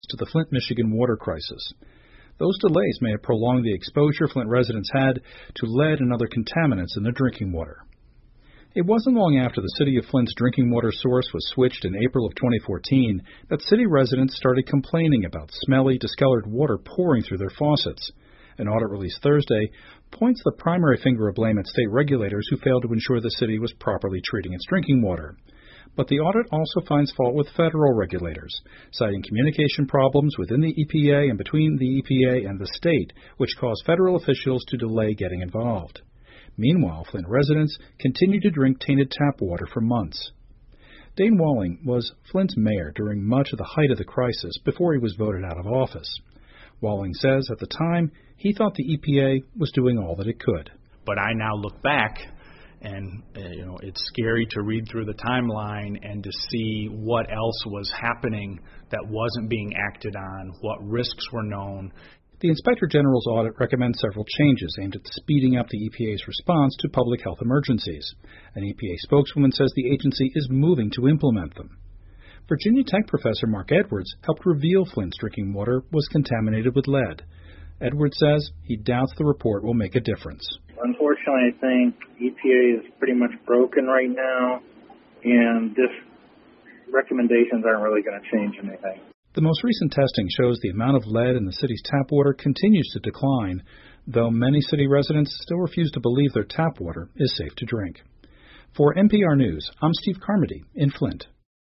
密歇根新闻广播 对环保署处理弗林特水危机的批评 听力文件下载—在线英语听力室